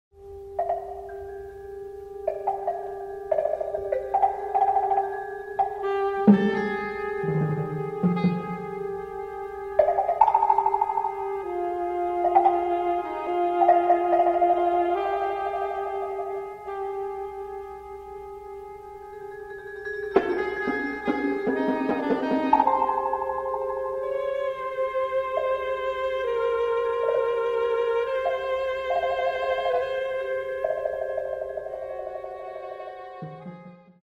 for Two Saxophonists and Three Percussionists
Saxophone and Percussion